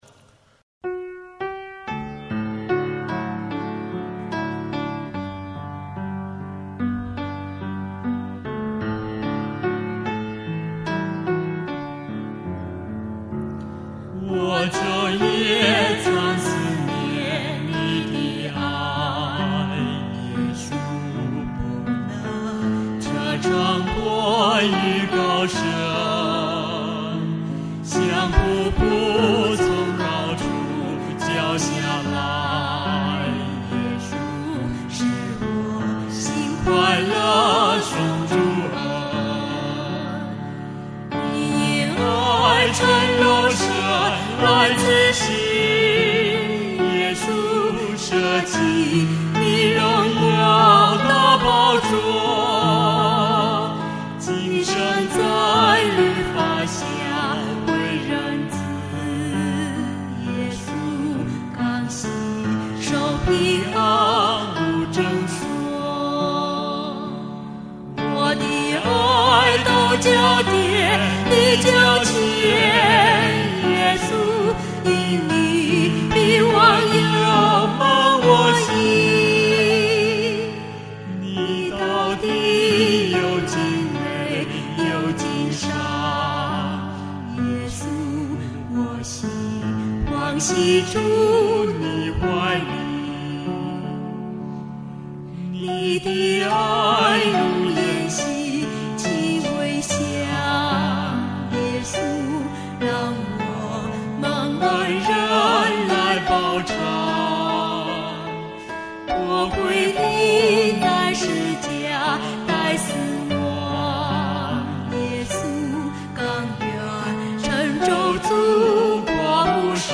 颂赞：《我昼夜常思念你的爱》